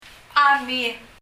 綴りがchで始まっていますが、私にはこのchは聞き取れません。
« fence 垣根 chopstick 箸 » screen 網 chami [? ʌmi] 網戸の「網」、です。